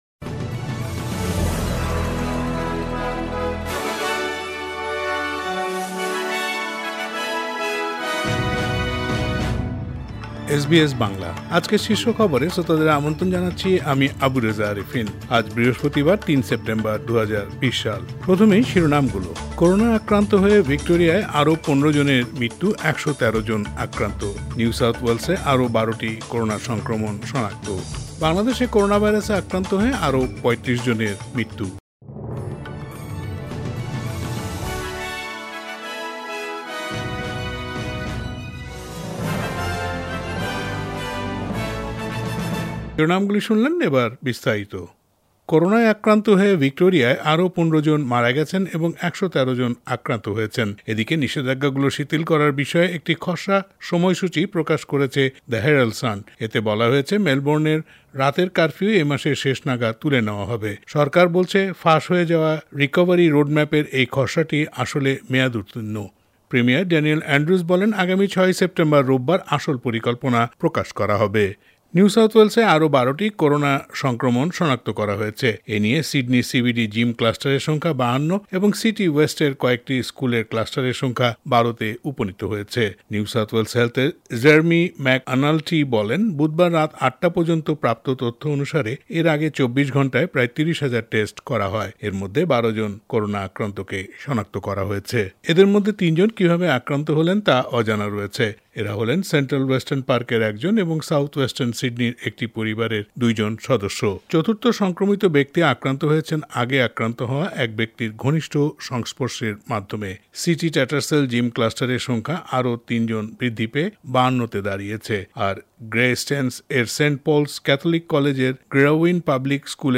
এসবিএস বাংলা শীর্ষ খবর: ০৩ সেপ্টেম্বর ২০২০